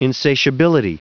Vous êtes ici : Cours d'anglais > Outils | Audio/Vidéo > Lire un mot à haute voix > Lire le mot insatiability
Prononciation du mot : insatiability